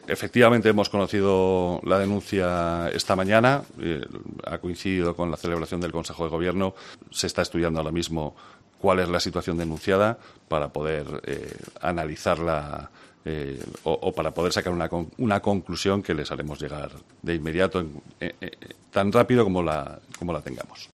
Chus del Río, portavoz del Gobierno de La Rioja